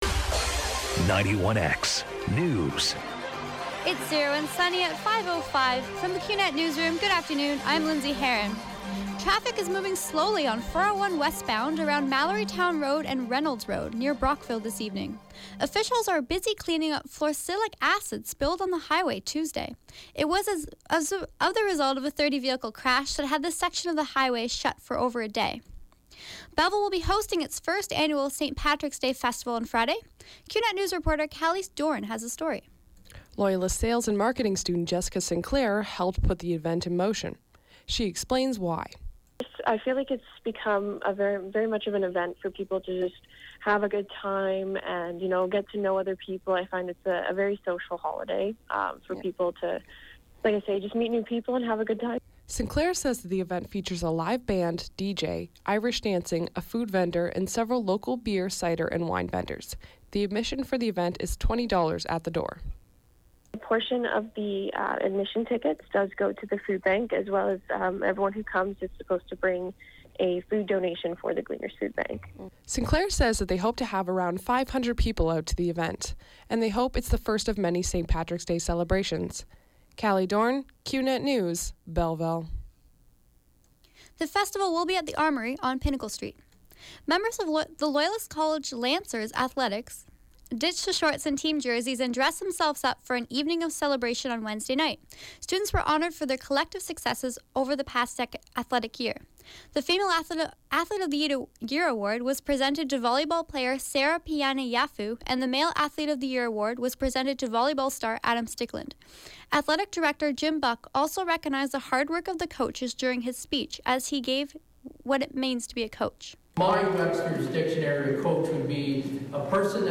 91X FM Newscast — Thursday, March 16, 2017, 5 p.m.